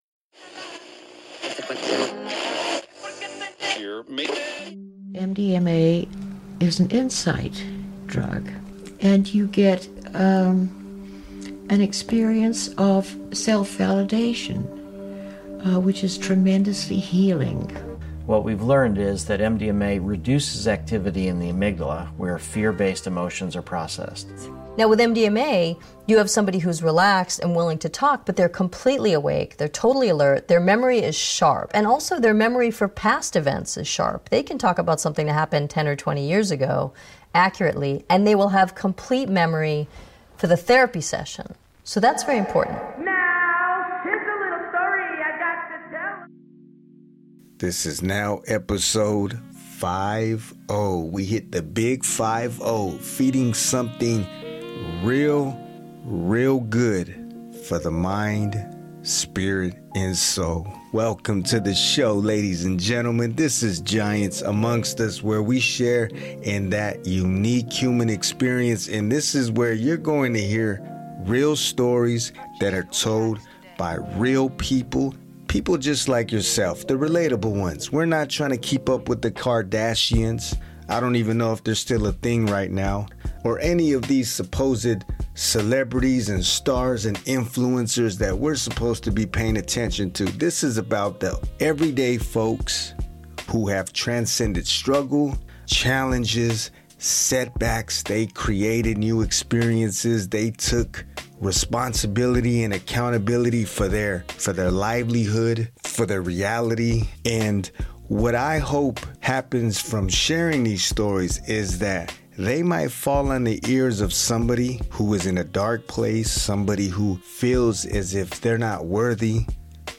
This will have graphic, and sensitive content. It's a raw conversation about sexual abuse, and the journey towards healing.